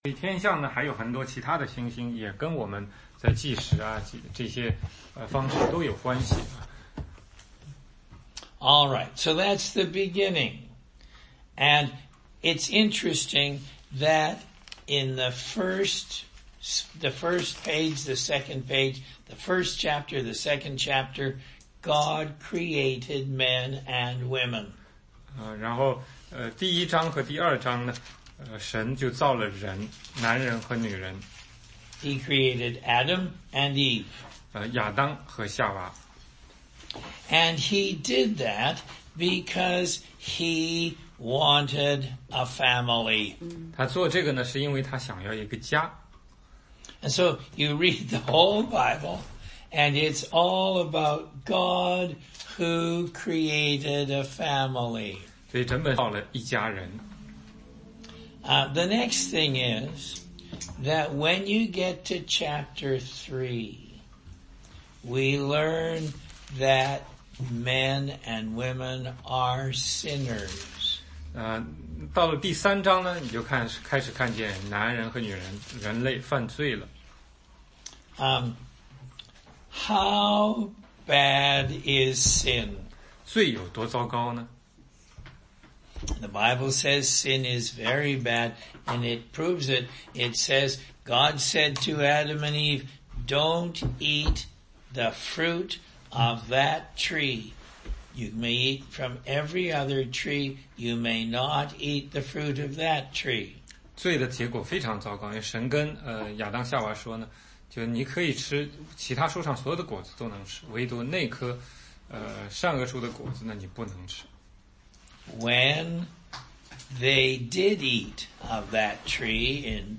16街讲道录音 - 福音基础1